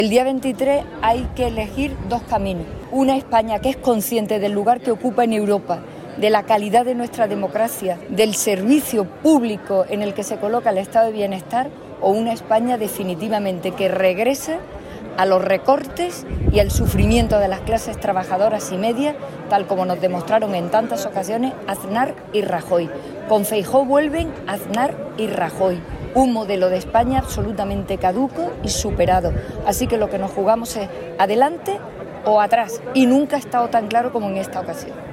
Expresiones que forman parte del mitin ofrecido anoche en la Plaza del Ángel bastetana, al que hace referencia la comunicación que nos han enviado desde el propio PSOE y que junto a las notas de audio y la imagen que la acompañan, reproducimos a continuación: